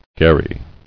[ghar·ry]